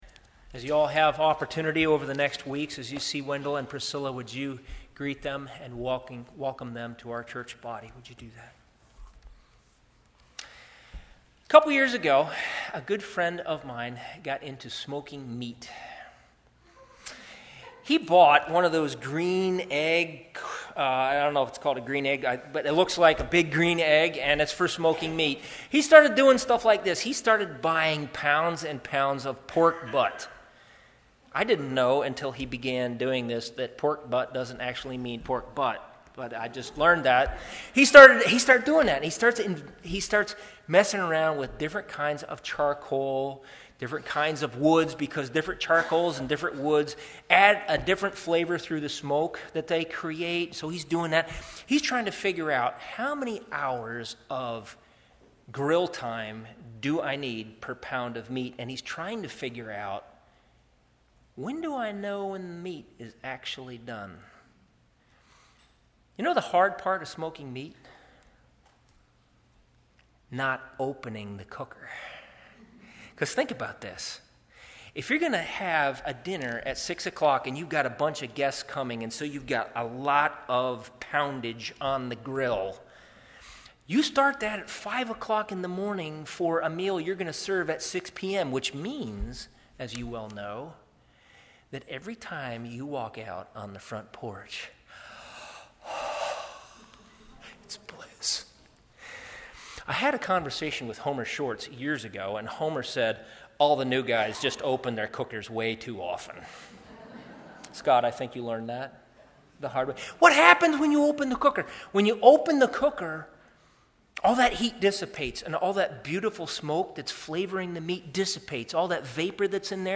Sermons on 1 Peter 1:10-12 — Audio Sermons — Brick Lane Community Church